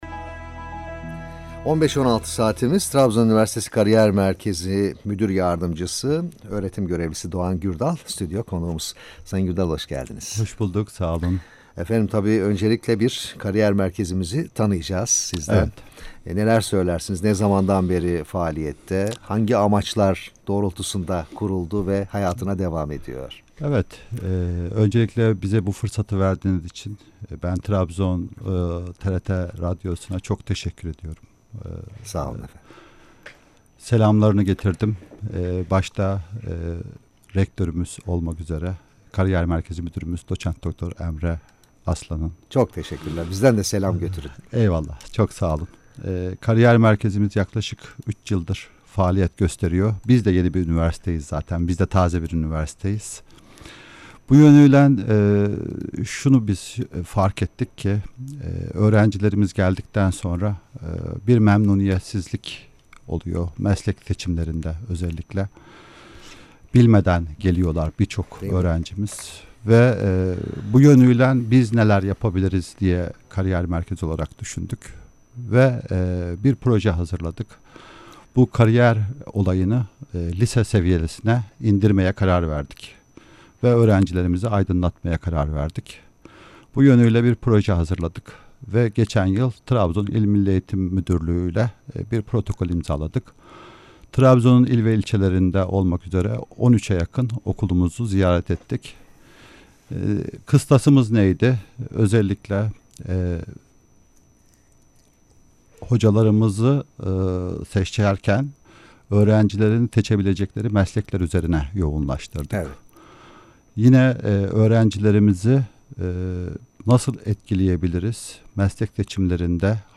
Canlı yayın kayıtlarını dinlemek için tıklayınız